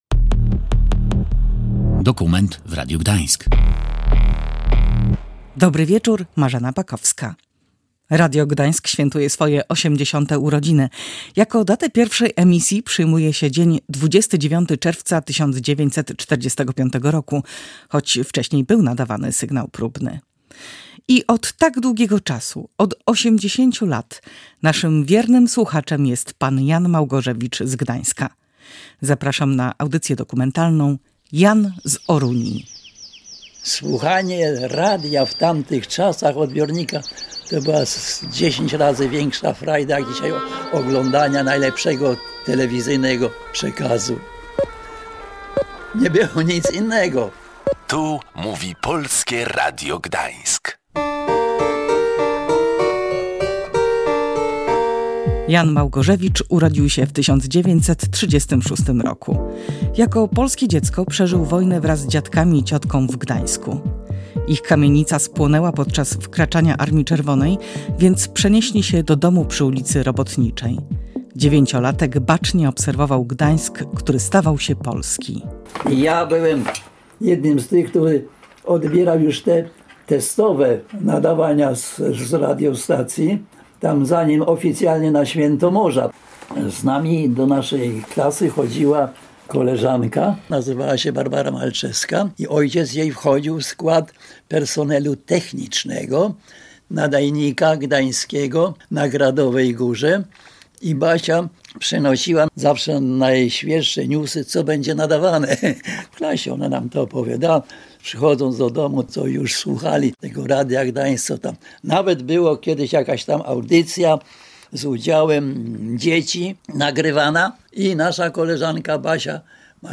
Słuchacz Radia Gdańsk od 1945 r. Posłuchaj reportażu
wykorzystano fragmenty z radiowego archiwum.